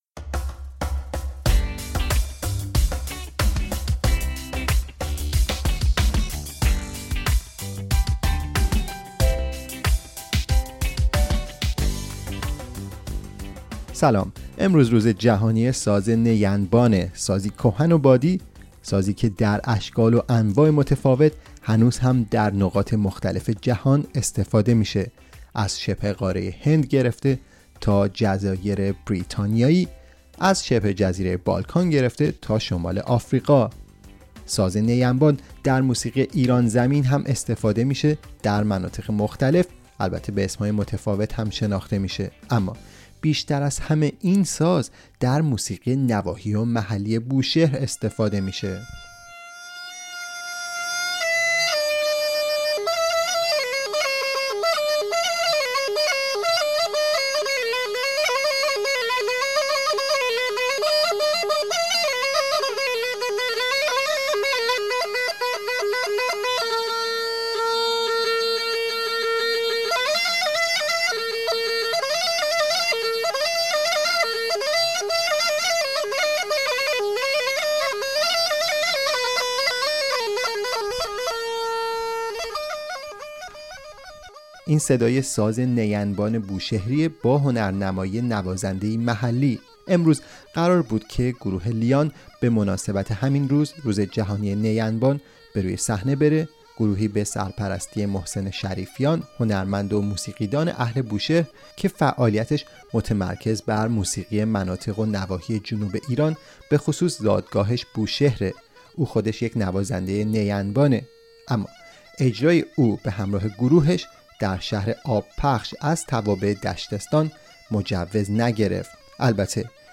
امروز روز جهانی ‌نی‌انبان است، سازی کهن و باستانی که در گوشه و کنار دنیا اشکال متفاوتی به خود گرفته و صدای متفاوتی دارد.